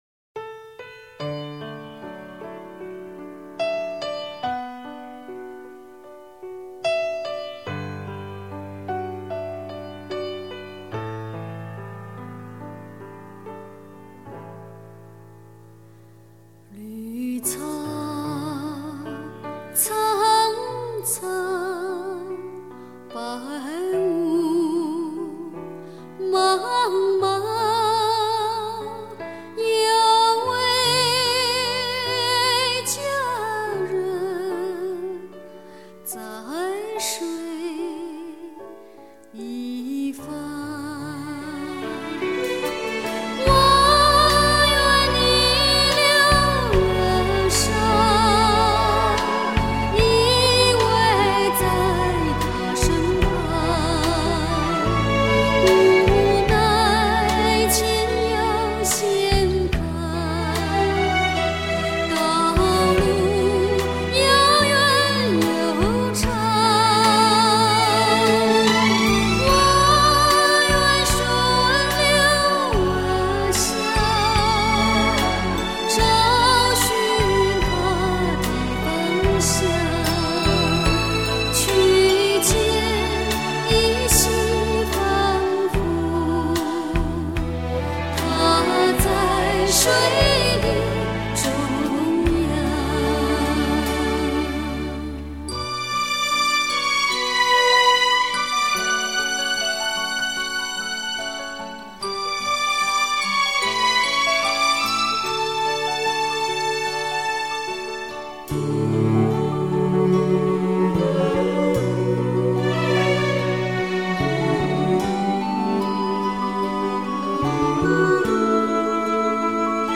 轻柔美声